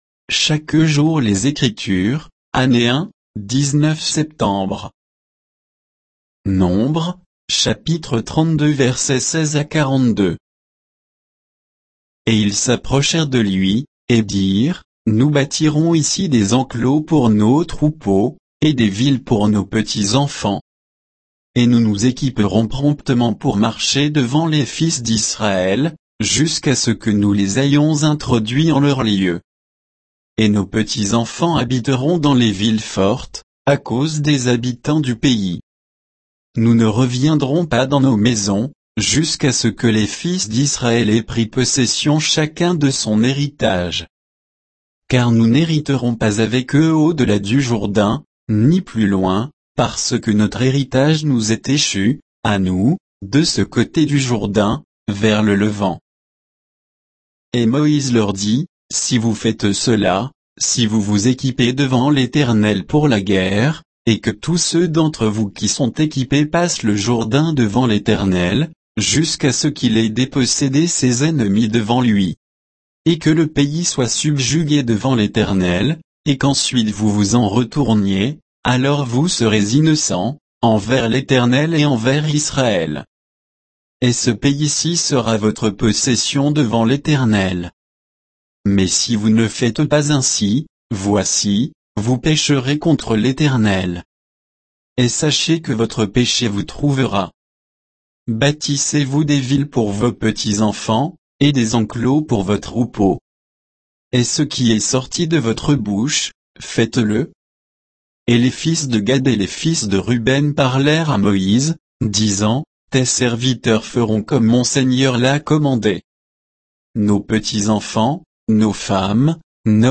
Méditation quoditienne de Chaque jour les Écritures sur Nombres 32, 16 à 42